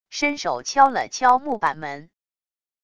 伸手敲了敲木板门wav音频